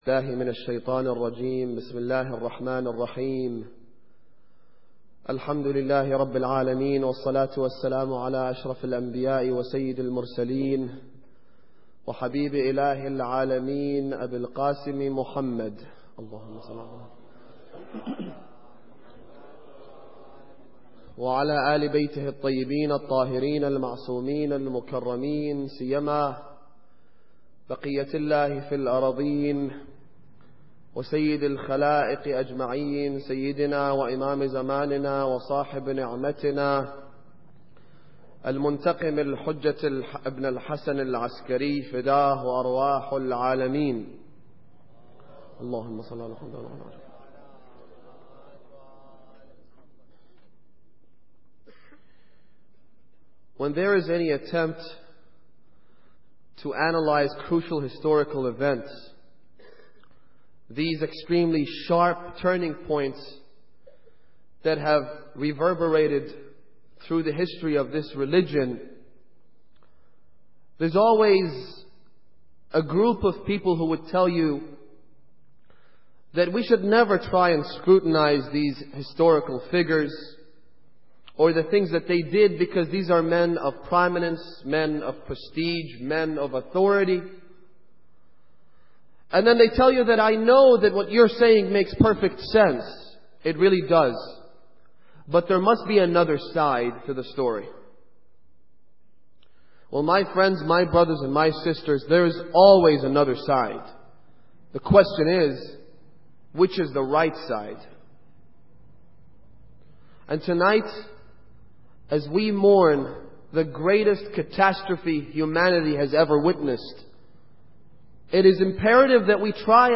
Muharram Lecture 8